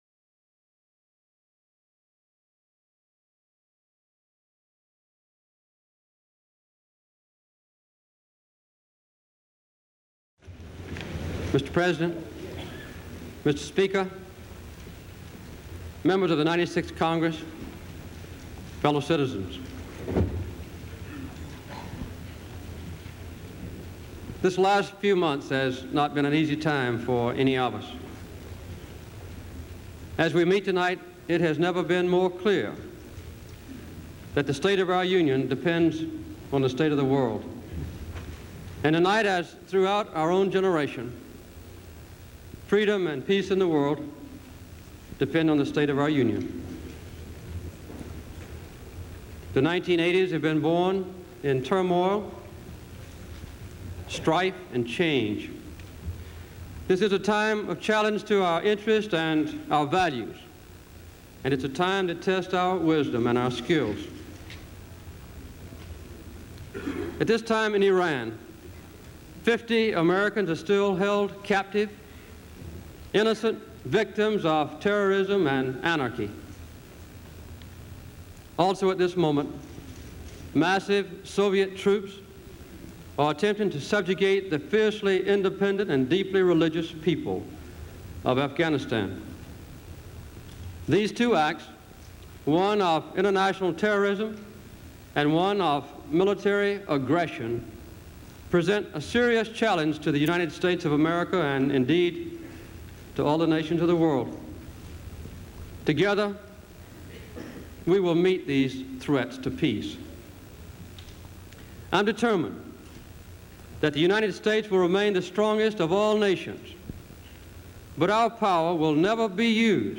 January 23, 1980: State of the Union Address
Presidential Speeches | Jimmy Carter Presidency